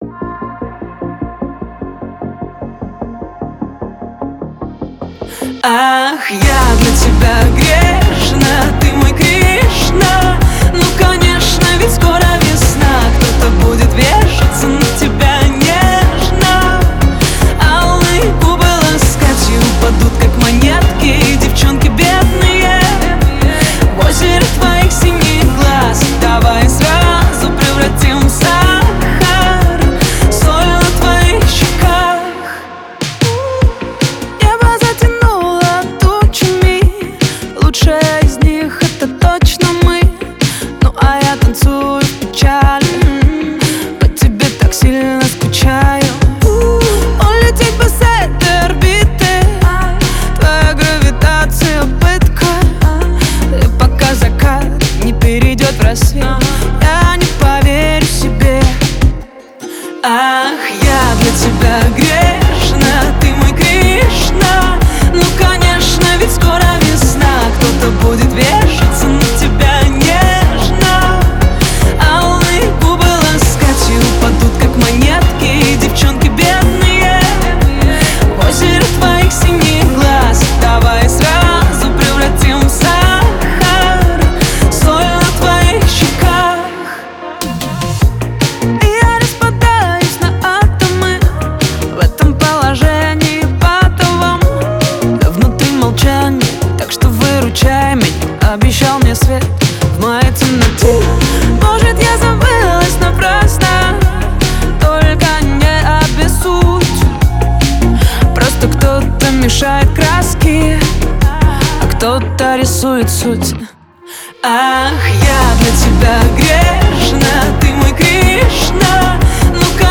RUS, Caver, Romantic, Dance, Pop, Funk | 20.03.2025 21:39